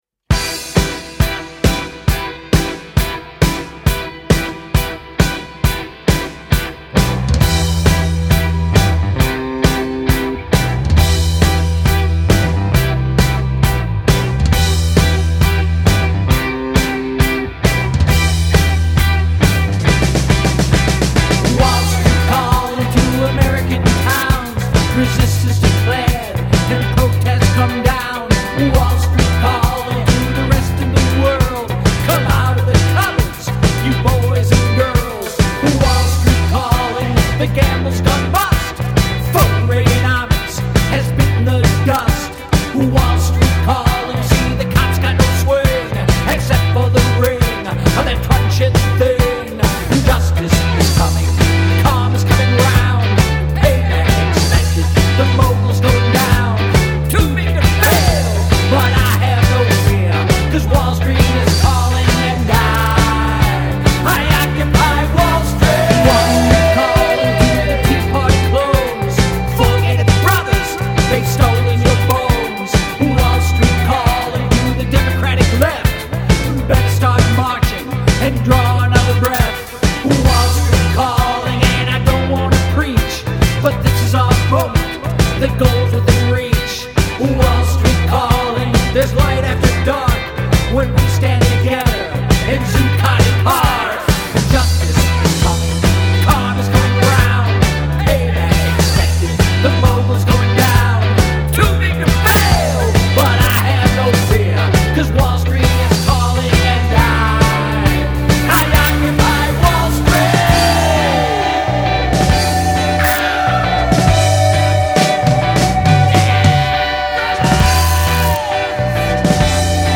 a marching song for the Occupy Wall Street movement.